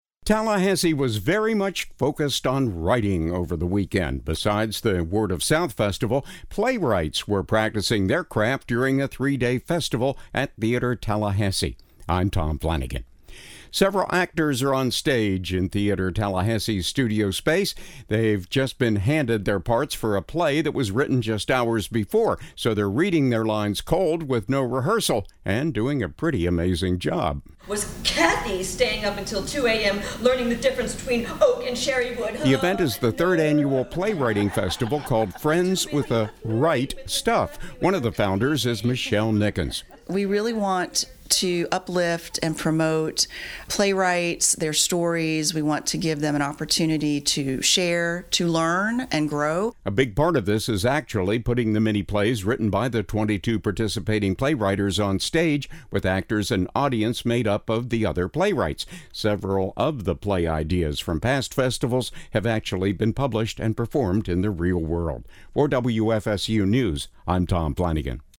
Several actors are onstage in Theatre Tallahassee’s Studio space.
So they’re reading their lines cold, with no rehearsal.
“With Cathy staying up until 2 a.m. learning the difference between oak and cherry wood…Nooooo!” (audience laughs) “We’re too busy flirting with Mr. Buckley with her cleavage to care about doing her job!!!” snarls one of the actors.